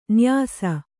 ♪ nyāsa